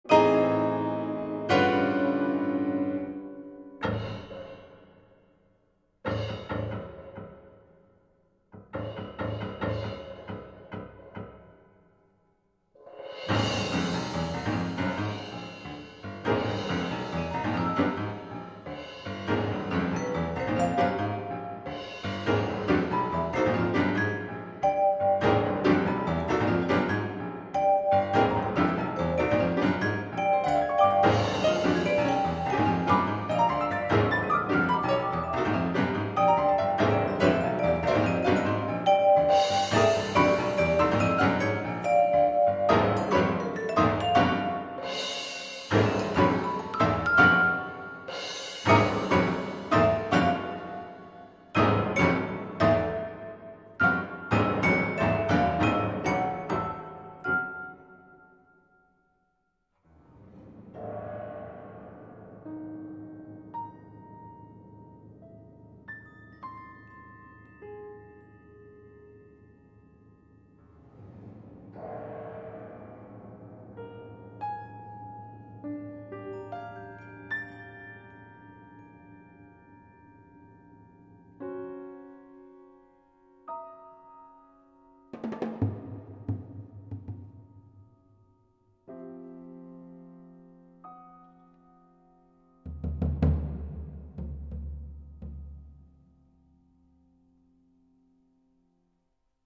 for piano 4 hands, celesta and percussion